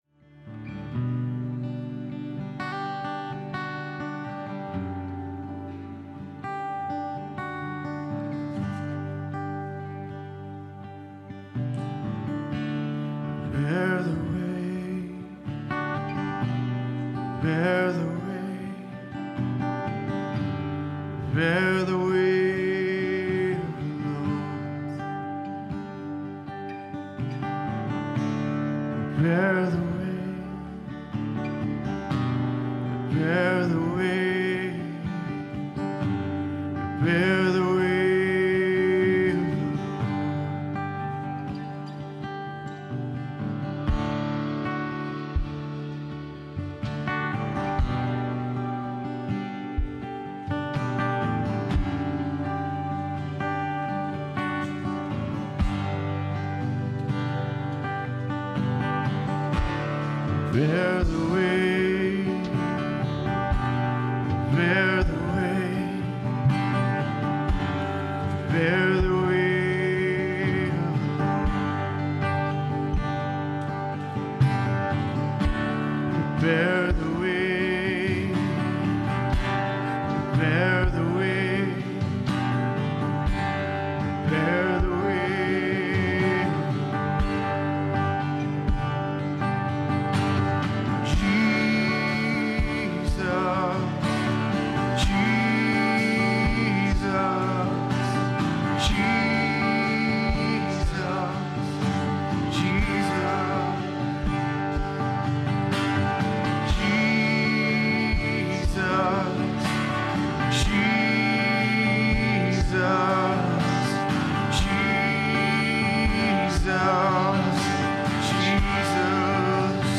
Sunday Morning Worship (music portion) Date July 16, 2023 Speaker The Cornerstone Worship Team Watch Listen Save Cornerstone Fellowship Sunday morning service, livestreamed from Wormleysburg, PA. This is the worship music portion at the beginning of the service.